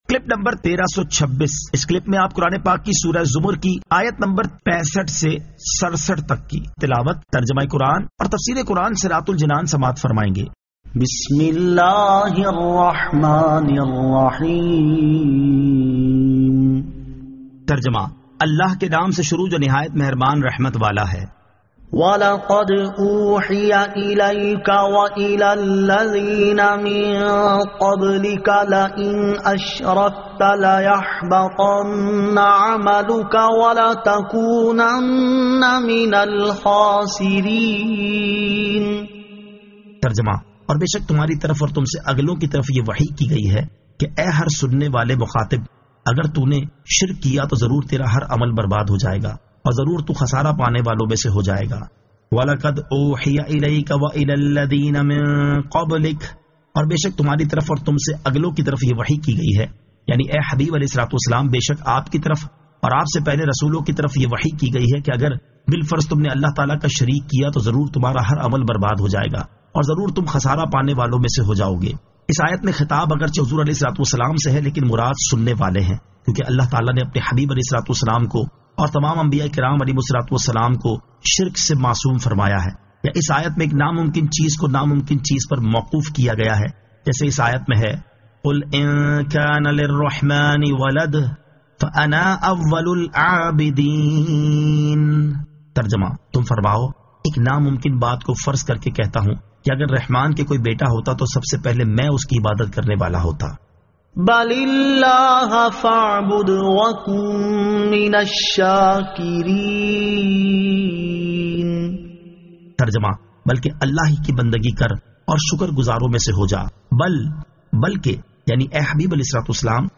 Surah Az-Zamar 65 To 67 Tilawat , Tarjama , Tafseer